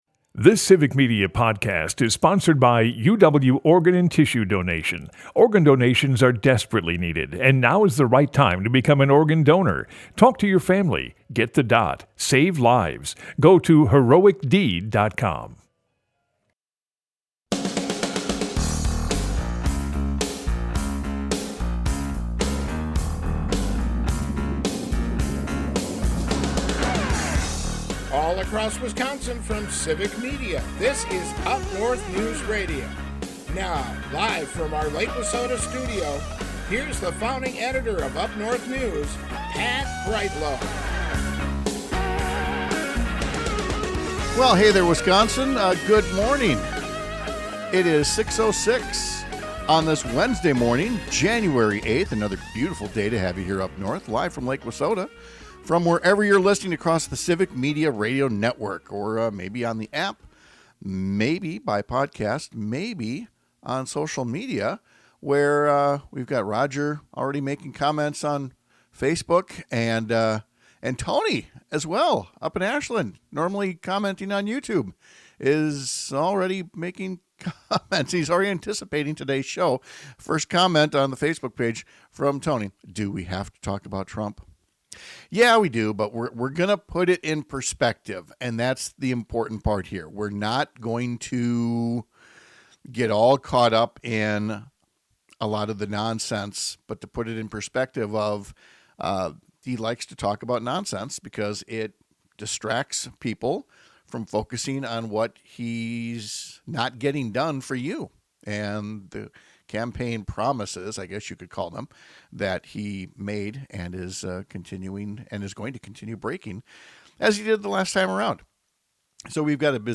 Broadcasts live 6 - 8 a.m. across the state!